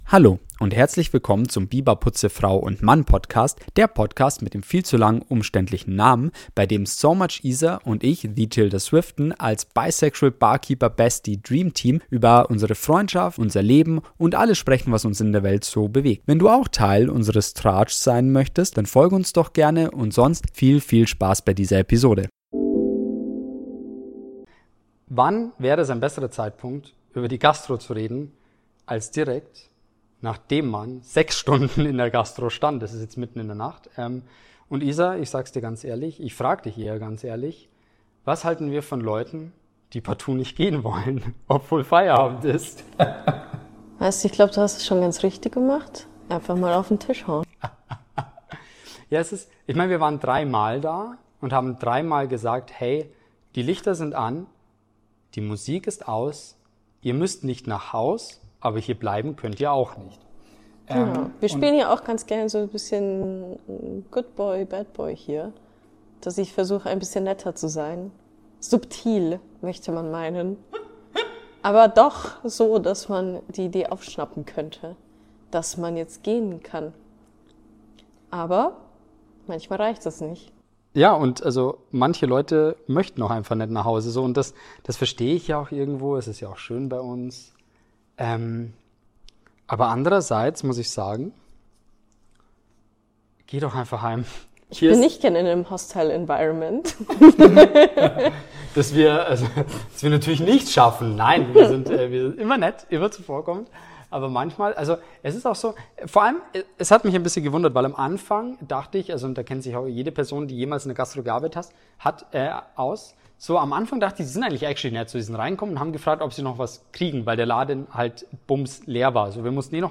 DISCLAIMER: Der Sound in dieser Episode ist technisch bedingt leider nicht entsprechend unserer sonstigen Qualität.